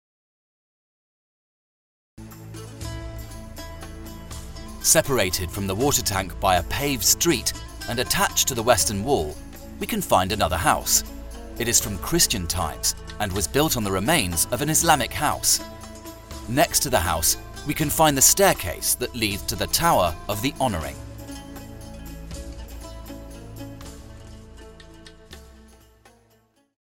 Ruta audioguiada